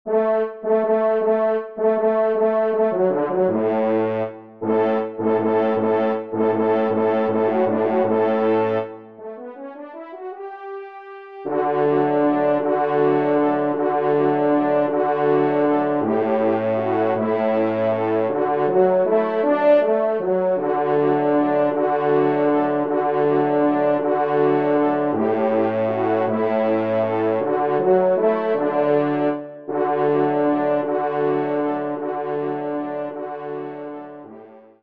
Genre :  Divertissement pour Trompes ou Cors en Ré
5e Trompe